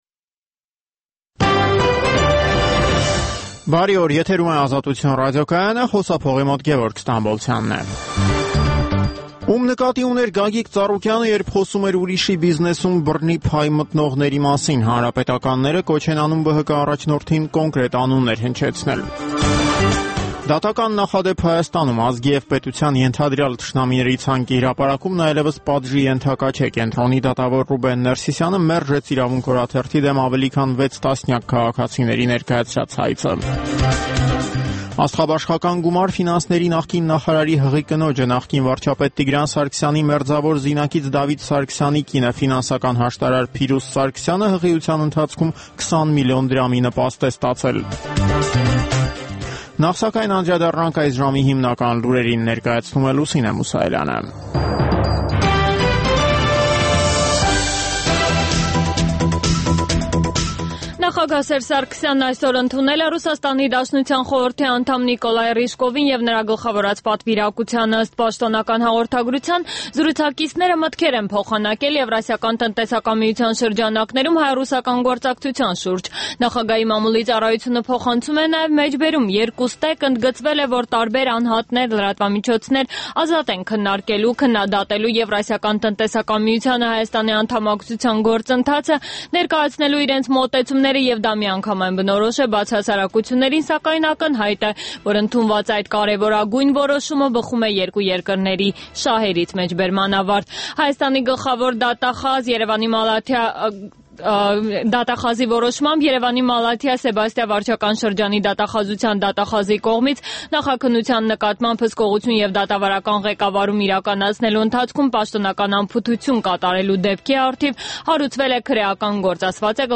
Տեղական եւ միջազգային լուրեր, ռեպորտաժներ զարգացող իրադարձությունների մասին, այդ թվում՝ ուղիղ եթերում, հարցազրույցներ, տեղական եւ միջազգային մամուլի տեսություն: